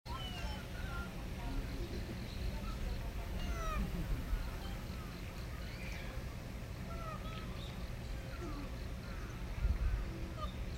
■ワオキツネザルの鳴き声
この声は、飼育員が食事の準備をしており、柵の前で待ち構えているときの声です。
gohan.mp3